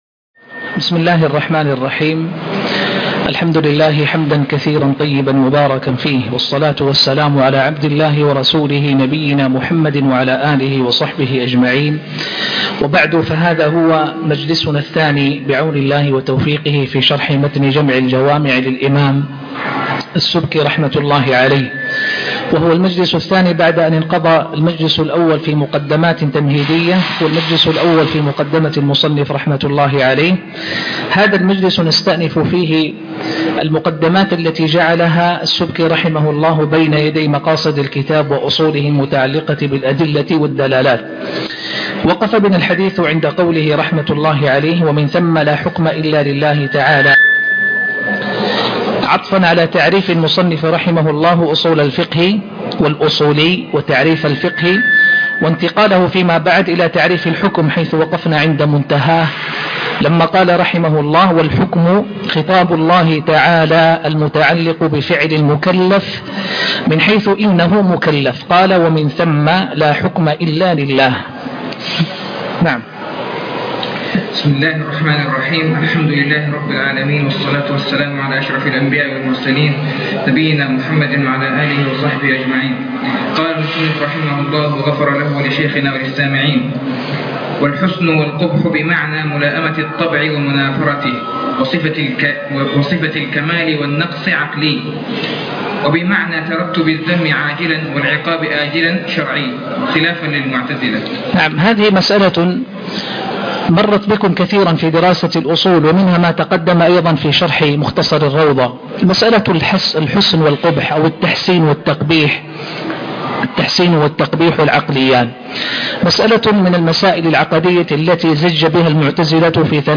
شرح جمع الجوامع الدرس 02 ( المقدمات - الحكم واقسامه ) 22